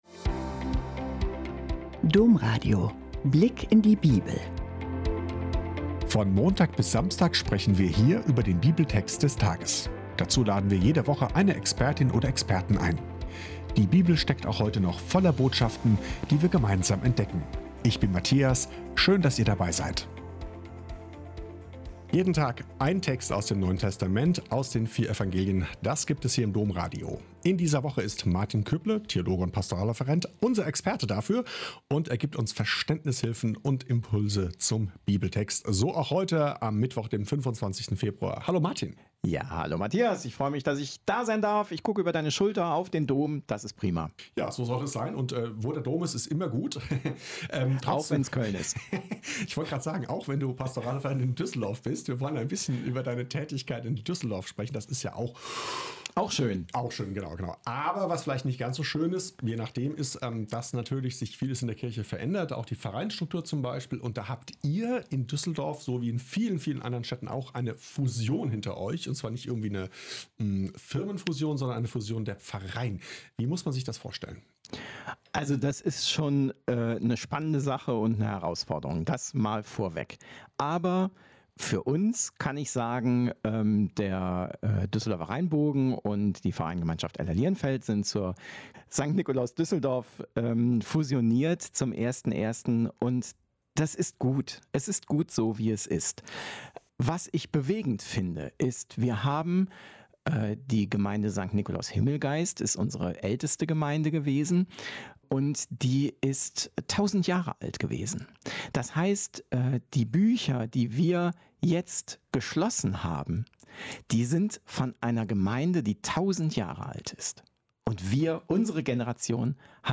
"Mehr als ein Zeichen“ - Gespräch